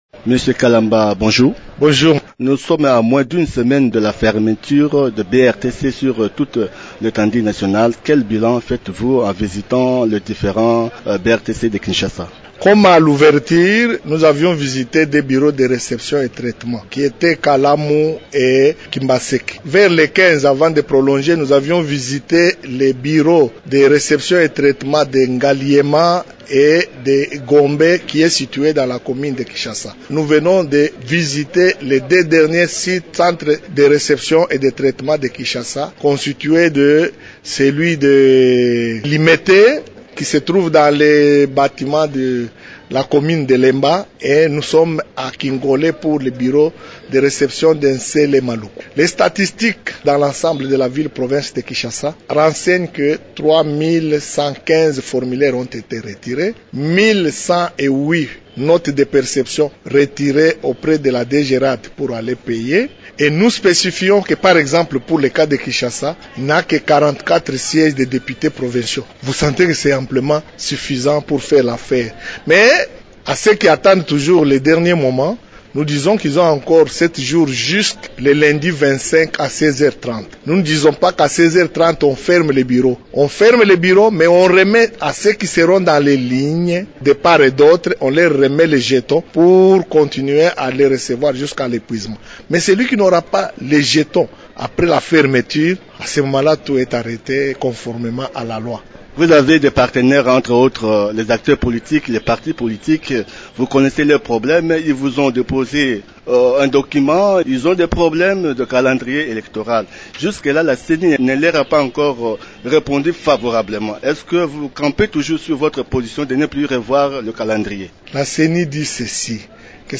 C’est ce que le  rapporteur de la Ceni a déclaré à Radio Okapi lors de la dernière descente des membres du bureau de la Ceni aux Bureaux de réception et de traitement des candidatures de Kinshasa.